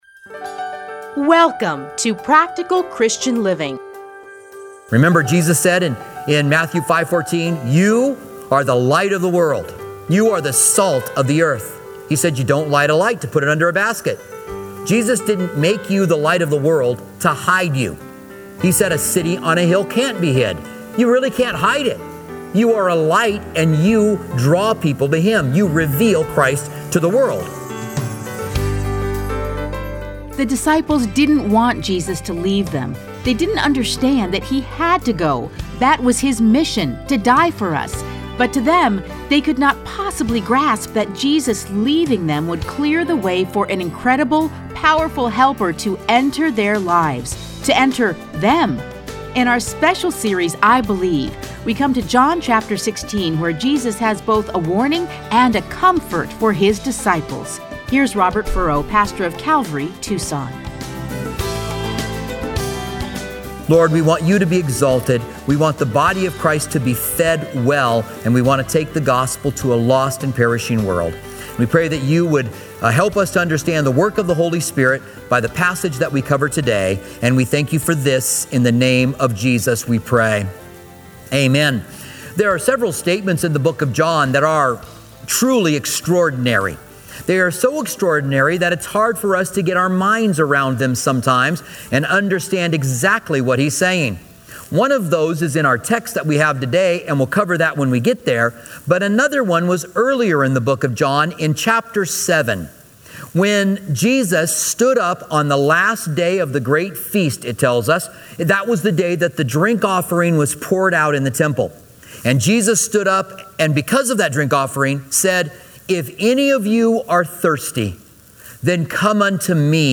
Listen to a teaching from John John 16:1-15 .